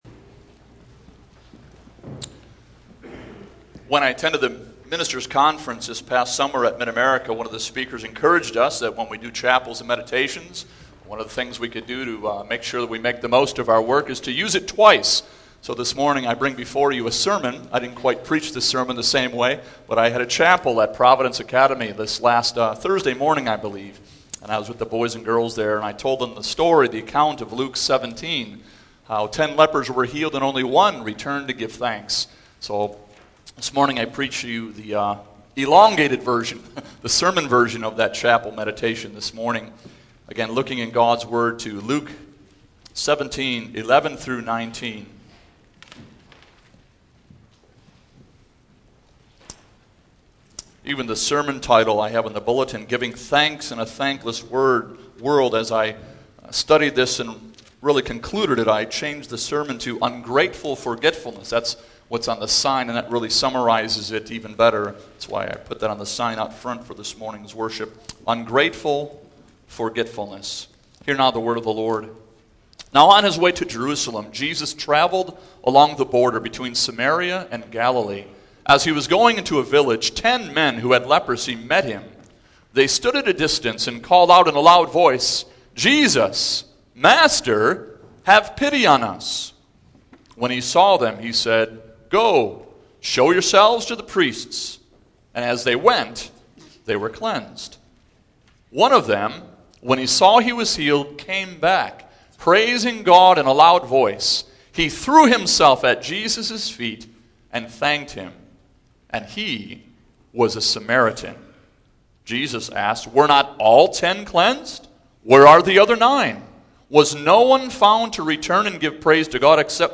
Single Sermons Passage: Luke 17:11-19 %todo_render% « Our Righteous Judge